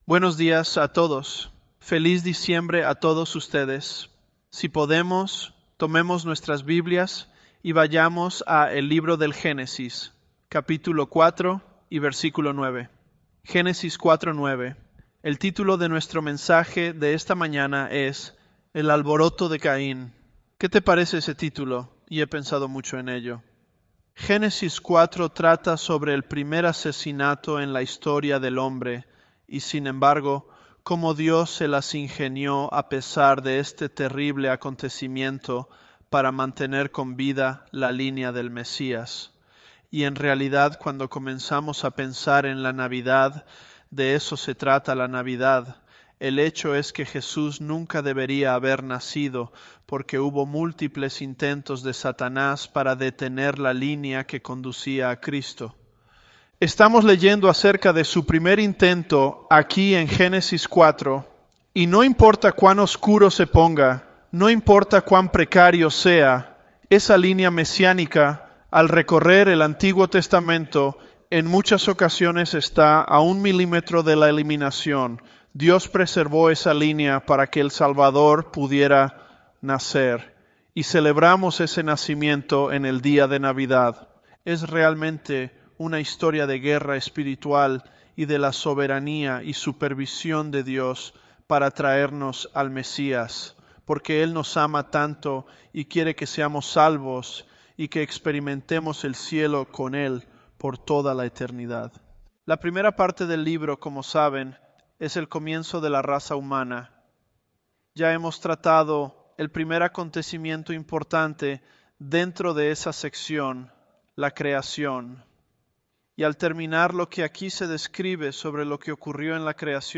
ElevenLabs_Genesis-Spanish017.mp3